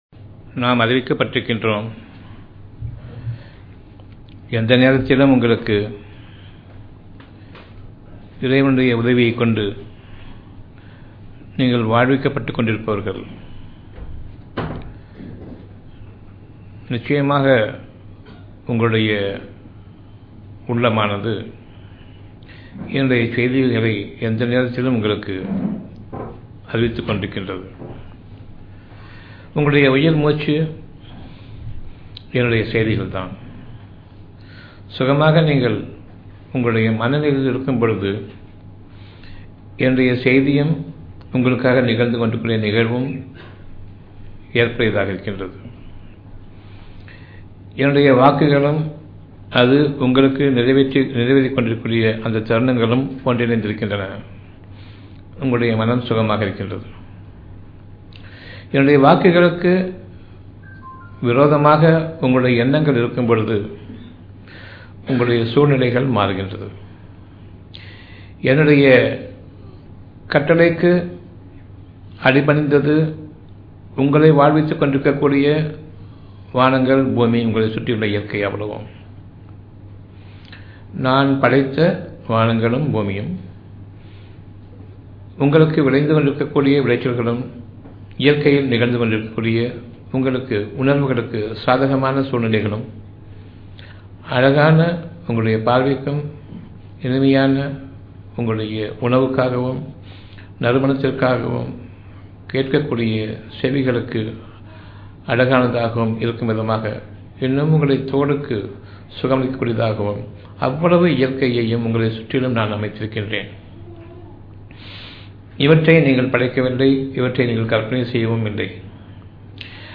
Audio Venue Sitra Auditorium